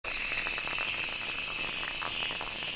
马桶喷水音效.MP3